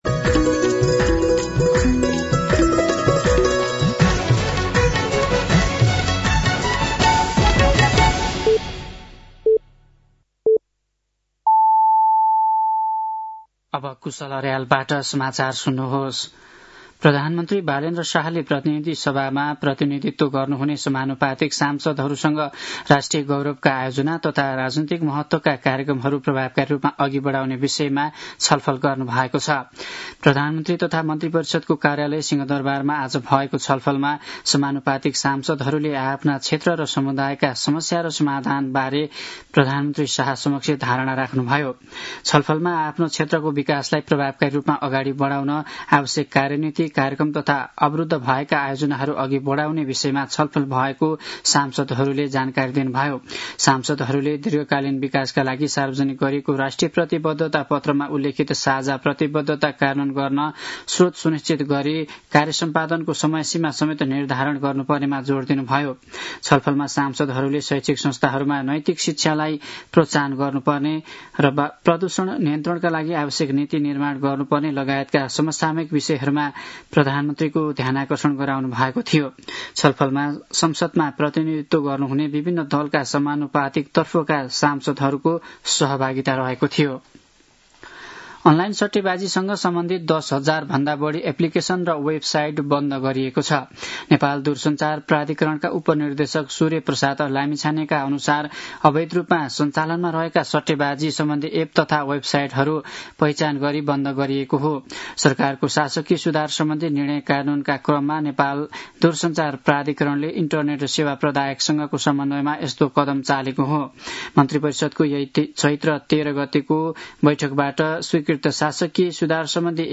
साँझ ५ बजेको नेपाली समाचार : २ वैशाख , २०८३
5-pm-news-3.mp3